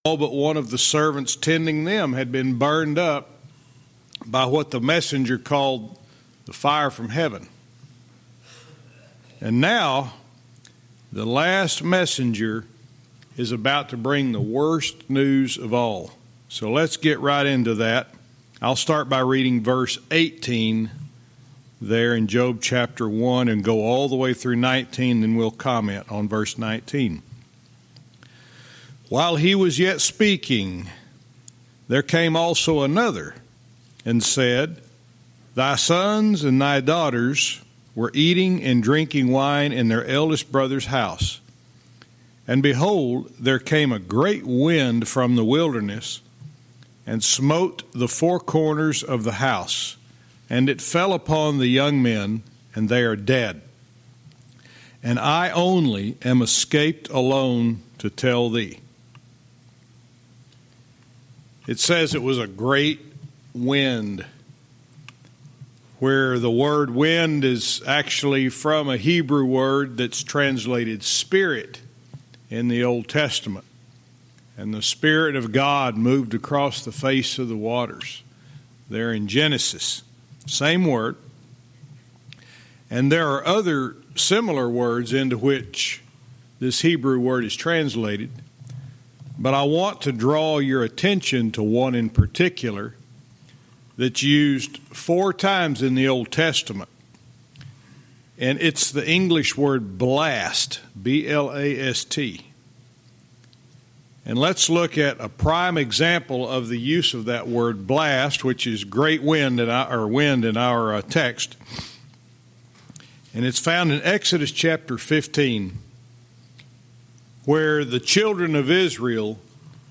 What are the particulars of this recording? Verse by verse teaching - Job 1:19-21 ***first minute is missing***